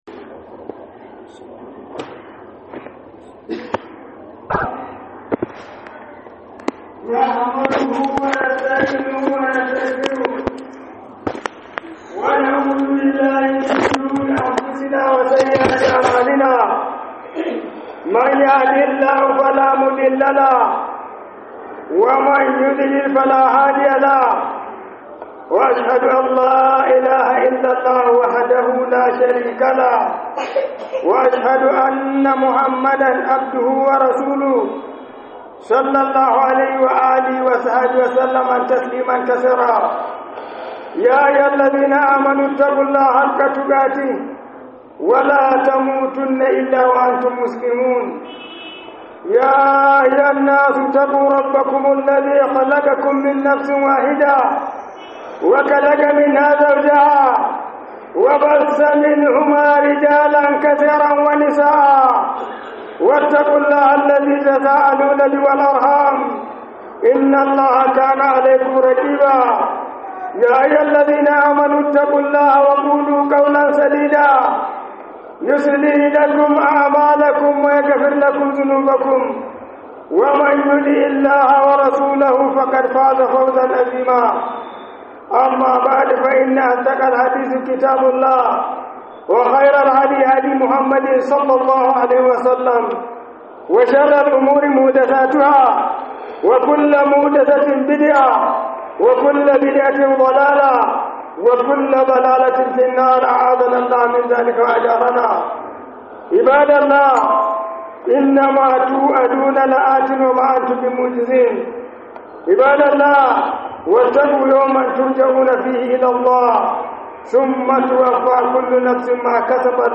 Khudubar NEPA Ningi - Biyayya wa iyaye